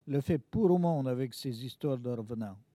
Localisation Saint-Hilaire-de-Riez
Catégorie Locution